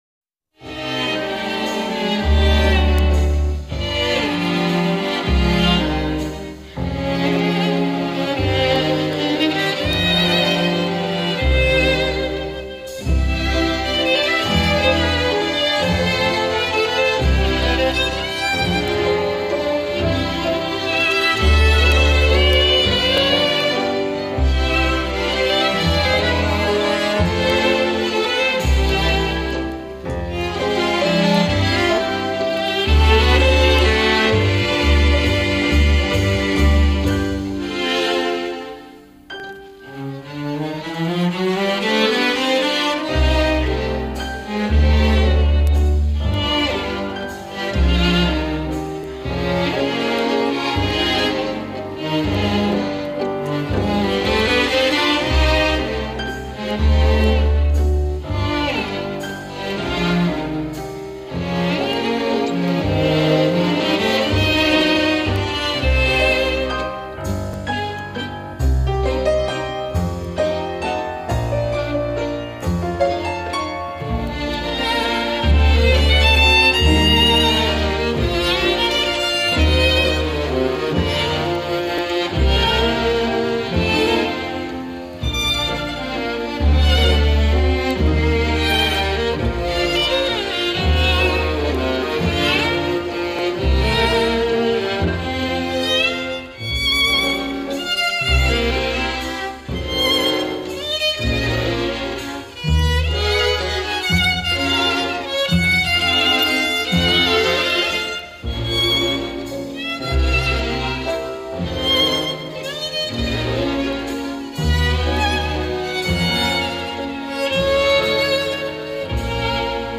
лиричный вальс-бостон